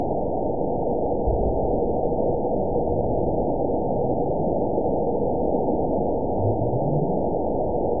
event 920407 date 03/23/24 time 23:28:35 GMT (1 year, 1 month ago) score 9.74 location TSS-AB02 detected by nrw target species NRW annotations +NRW Spectrogram: Frequency (kHz) vs. Time (s) audio not available .wav